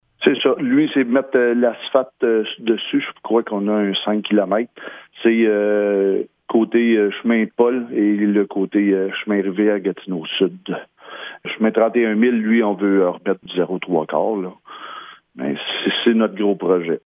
Le maire, Steve Lefebvre, explique quels types de travaux y seront réalisés :